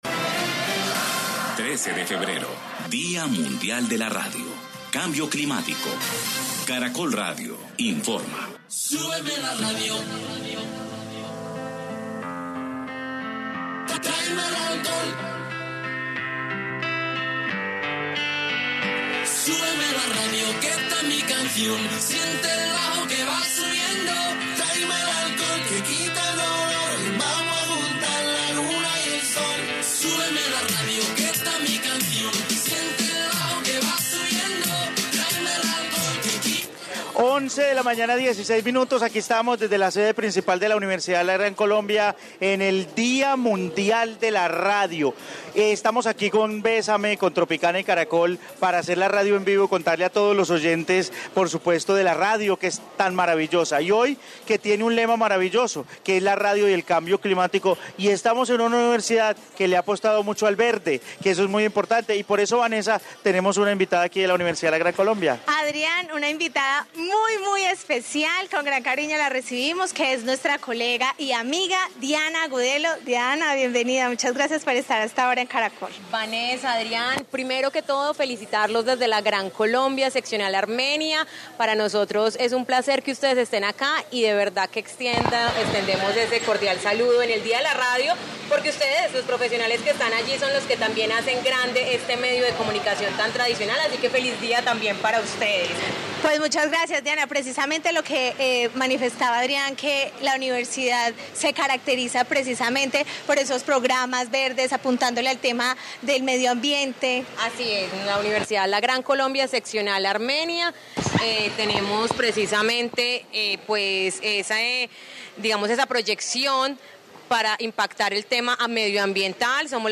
En Caracol Radio estuvimos desde la Universidad La Gran Colombia celebrando el Día Mundial de la Radio
Informe día mundial de la radio